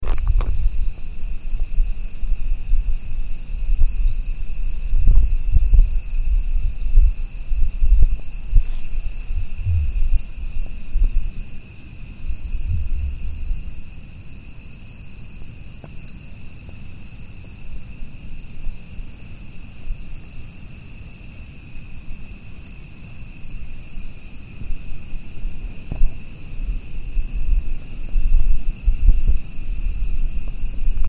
史丹吉氏小雨蛙 Micryletta steinegeri
雲林縣 斗六市 檨仔坑
錄音環境 水溝
50隻以上競叫